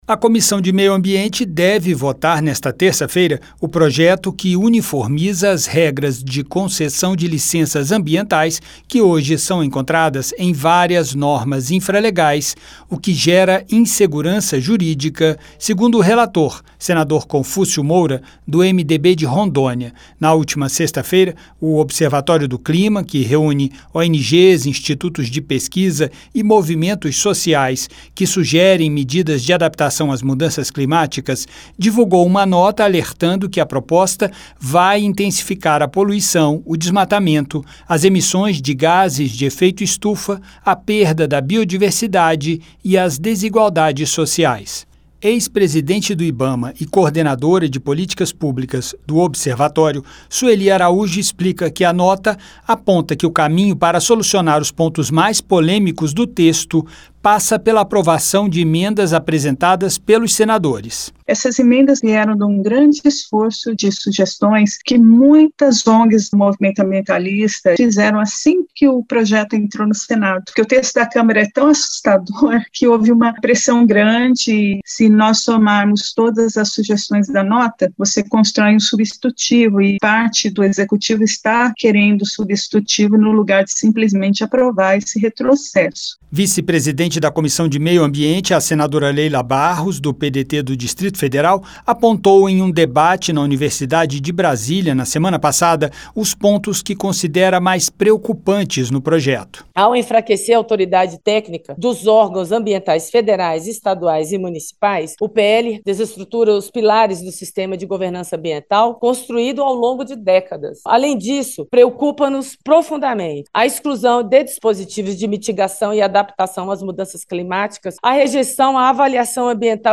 O enfraquecimento dos órgãos técnicos ambientais e a redução das garantias de participação social também foram criticados pela senadora Leila Barros (PDT-DF).